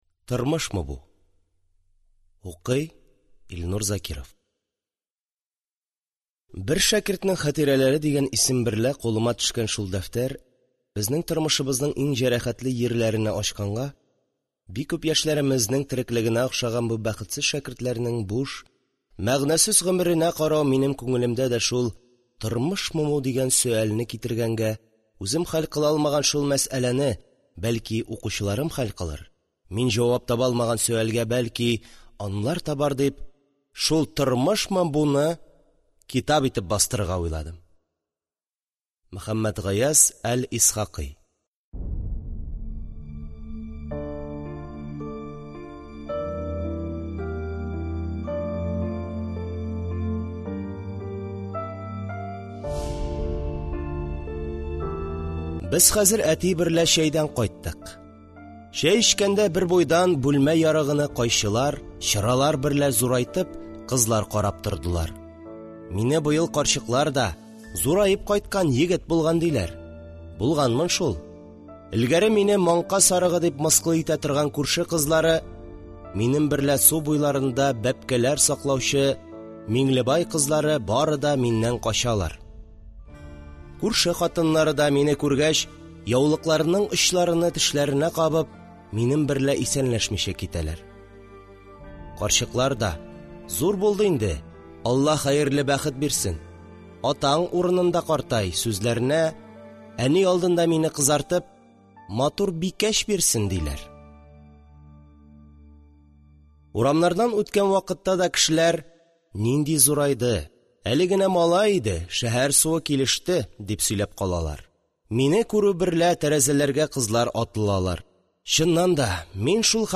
Аудиокнига Тормышмы бу | Библиотека аудиокниг